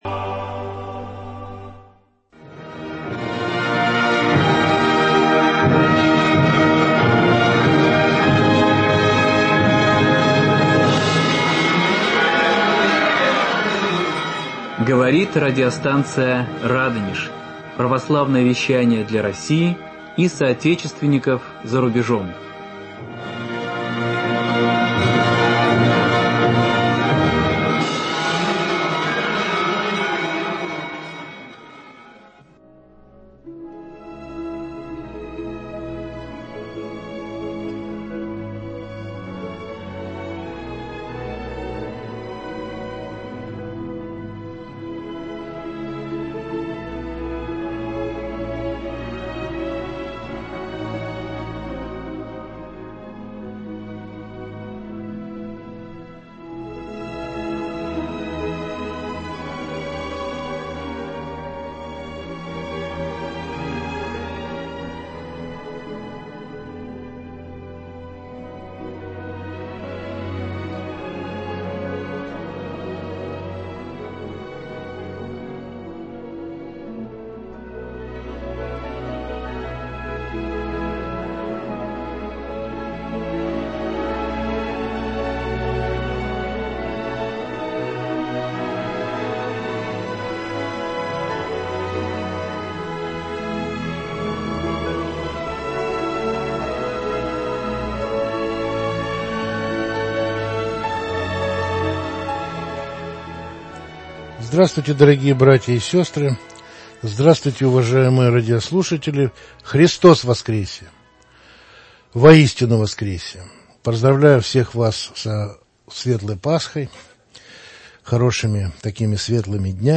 Священники, монахини, мирные жители, волонтеры, медицинские работники, воины - все с кем свела жизнь нашу радиостанцию за годы военного противостояния, кто терпеливо несет на себе его тяготы и продолжает сражаться и молиться, защищать свою родную землю.